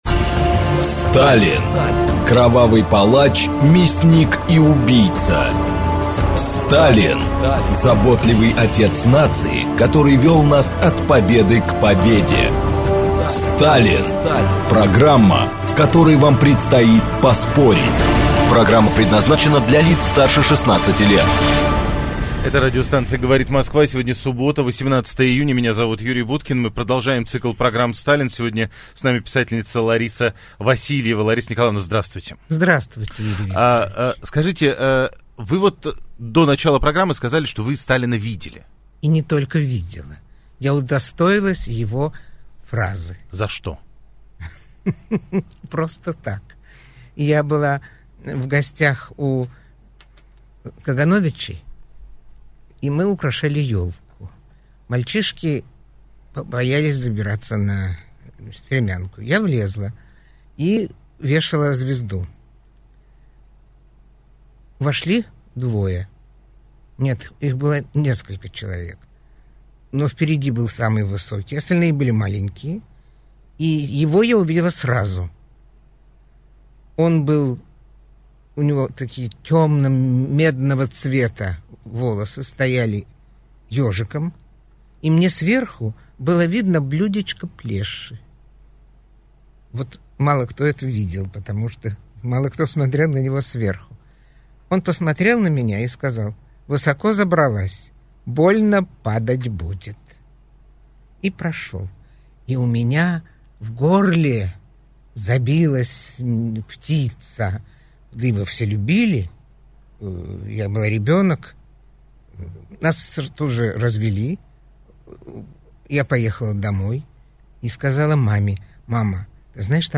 Аудиокнига Семья Иосифа Сталина | Библиотека аудиокниг
Прослушать и бесплатно скачать фрагмент аудиокниги